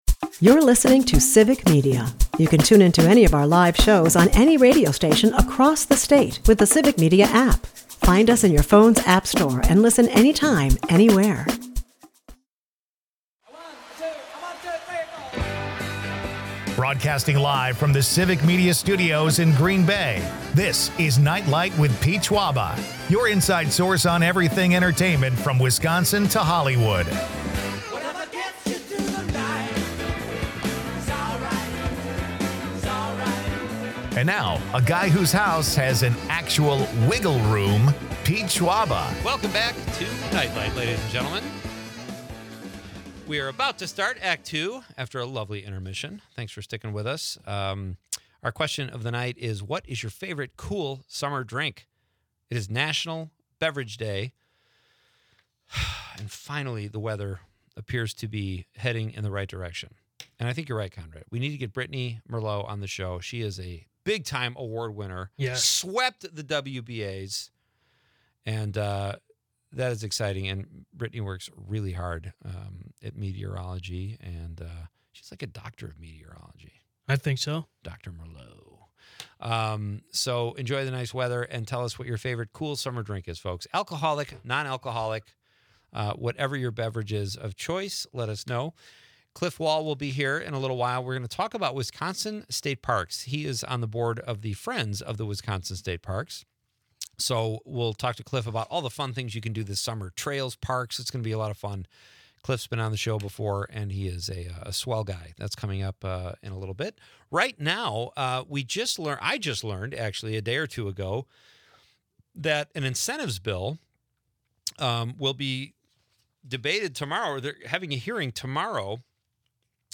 They take calls from the listeners and read texts, as well who very happy to share their thoughts with the gang about Tuesday night.